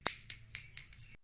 shellDrop4.wav